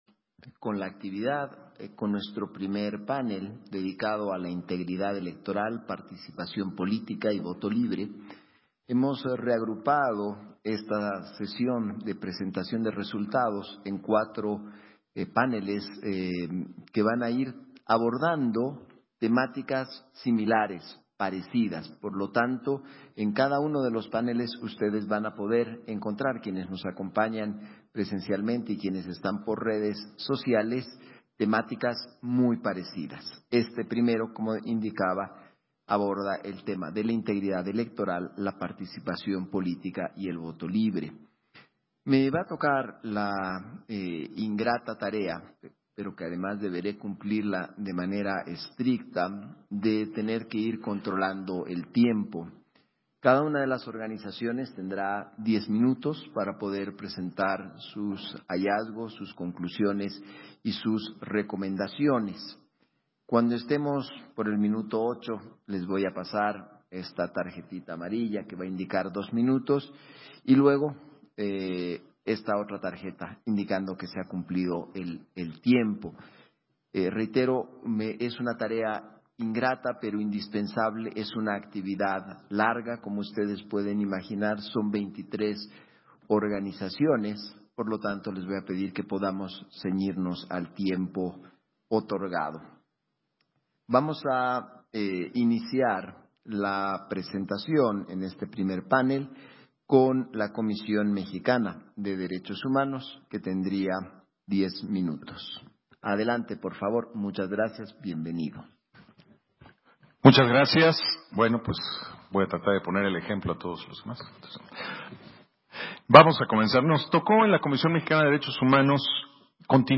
Primer panel, Participación política y voto libre
Versión estenográfica del primer panel, Participación política y voto libre, en el marco de la Presentación de hallazgos, conclusiones y recomendaciones de la Observación Electoral Nacional